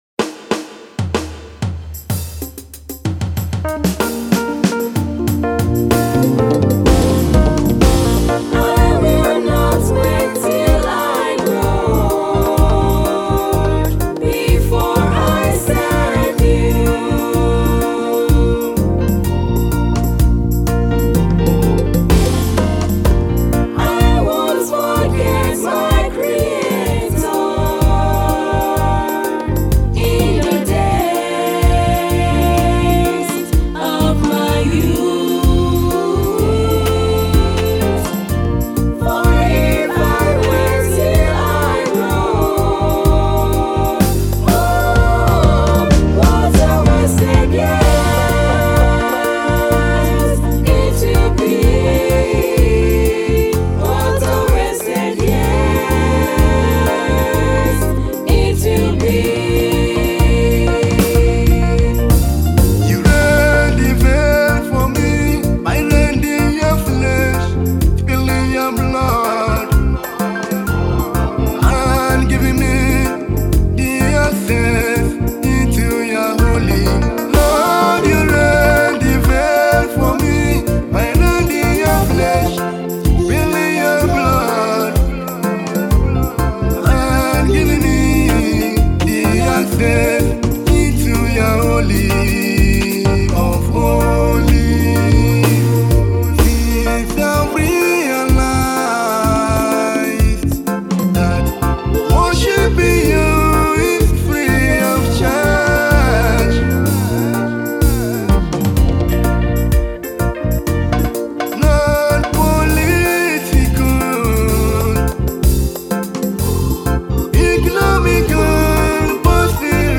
Gospel artist